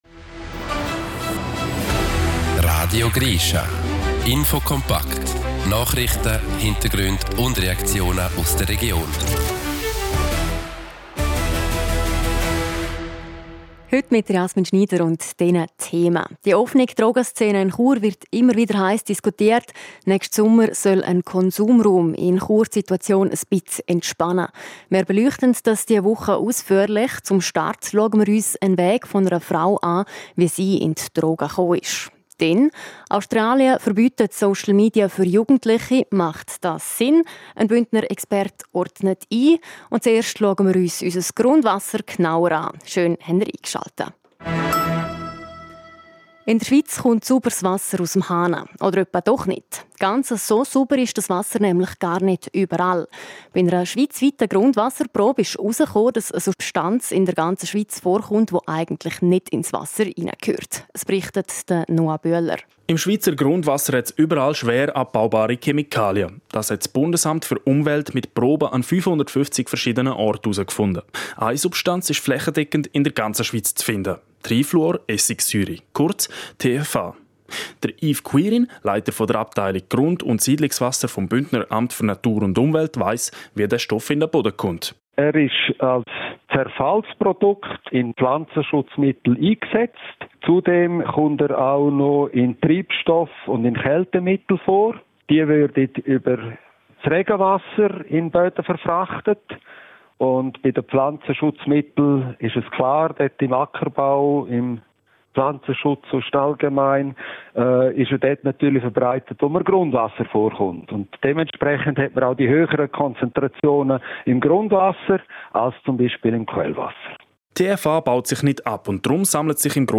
Zum Start unserer Wochenserie zum Thema Konsumraum in Chur erzählt eine 40-jährieg Bündnerin, warum sie Drogen konsumierte und wie sie davon wegkam.